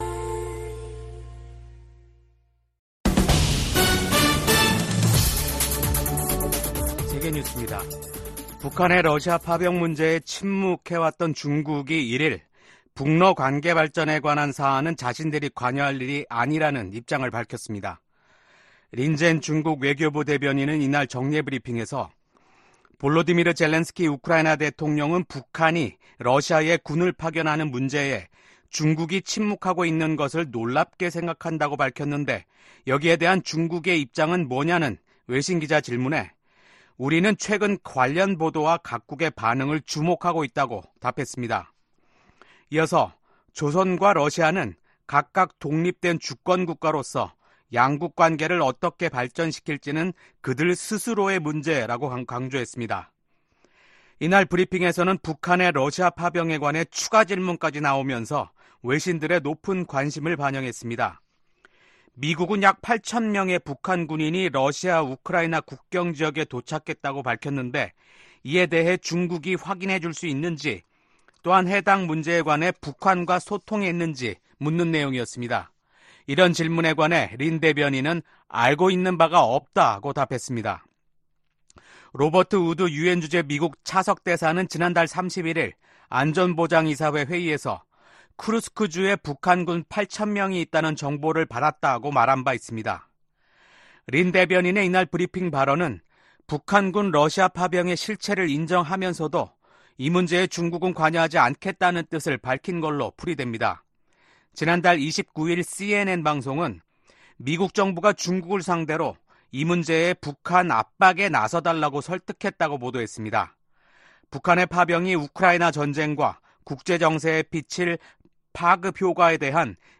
VOA 한국어 아침 뉴스 프로그램 '워싱턴 뉴스 광장' 2024년 11월 2일 방송입니다. 북한은 신형 대륙간탄도미사일(ICBM) ‘화성-19형’을 시험발사했고 이 ICBM이 ‘최종완결판’이라고 주장했습니다. 러시아에 파병된 북한군이 곧 전투에 투입될 것으로 예상된다고 미국 국무·국방장관이 밝혔습니다.